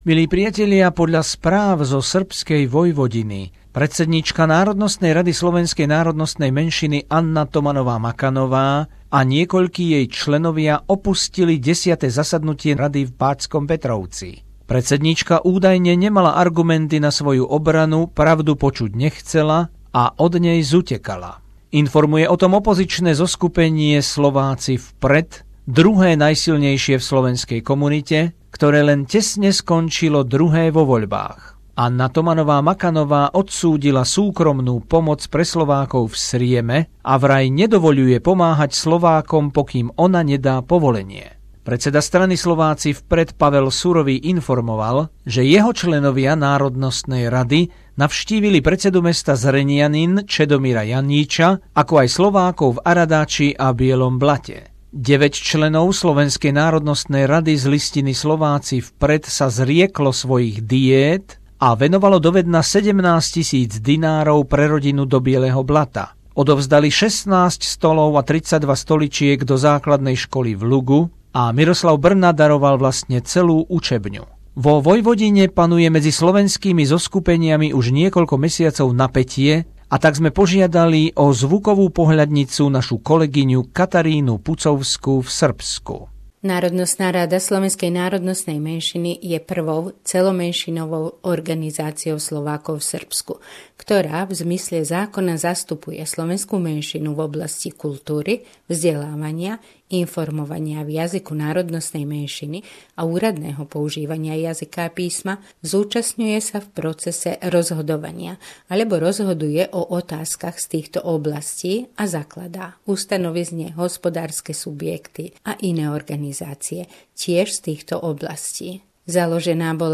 Zvuková pohľadnica